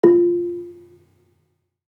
Gamelan Sound Bank
Gambang-F3-f.wav